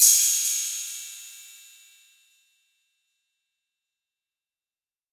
MB Perc (3).wav